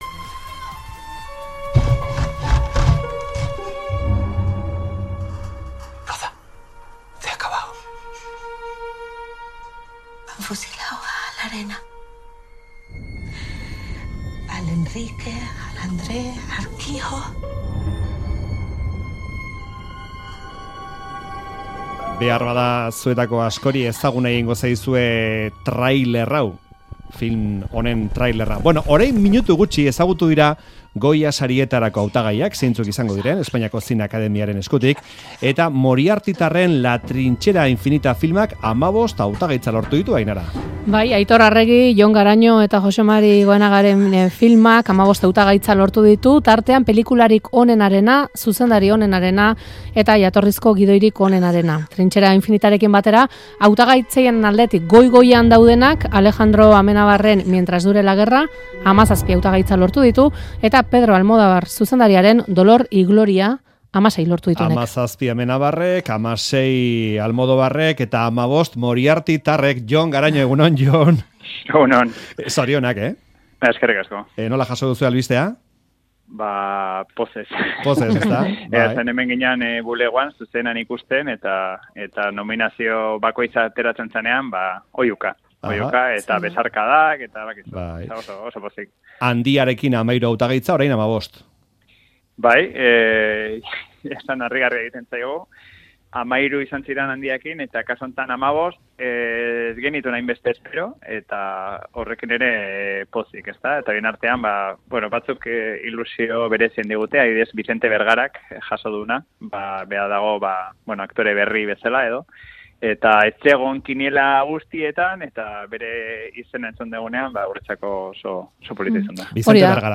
Audioa: Jon Garañori elkarrizketa Trinchera Infinitak 15 Goya izendapen lortuta